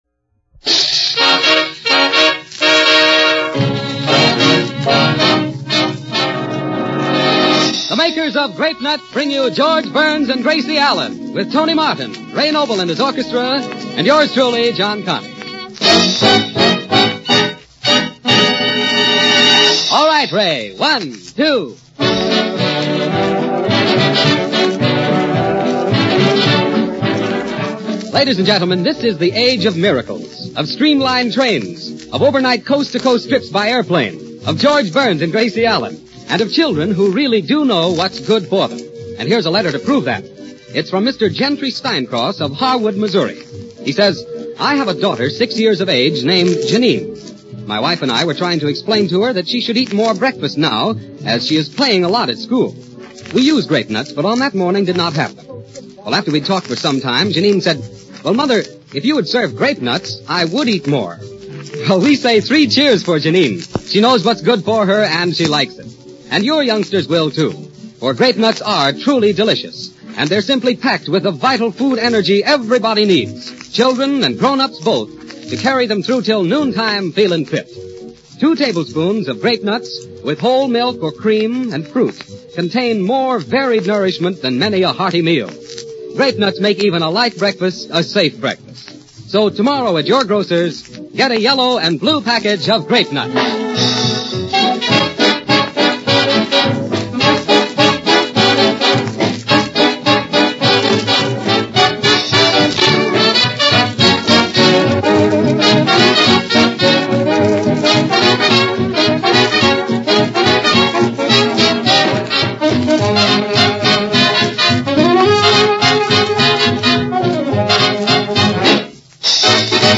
The Burns & Allen program from January 17, 1938 which includes a reference to Tacoma that will make you laugh if you catch it.